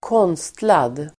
Uttal: [²k'ån:stlad]